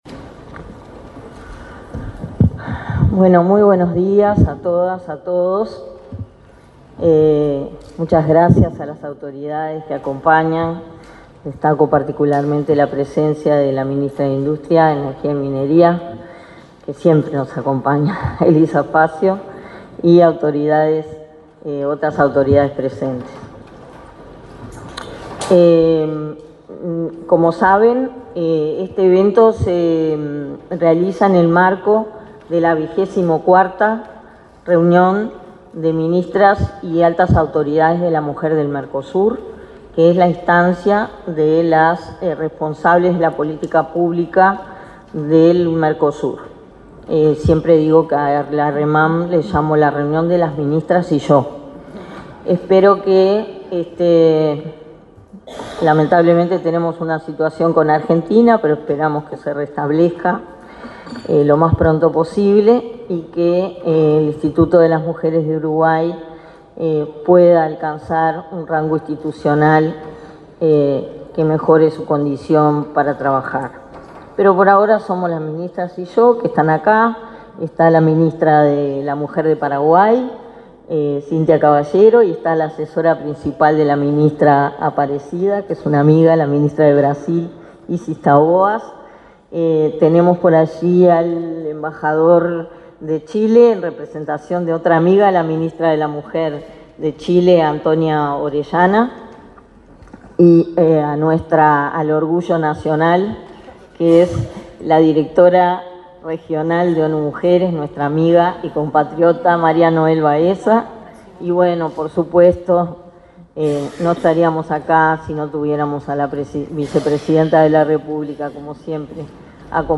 Palabras de autoridades en acto en el Palacio Legislativo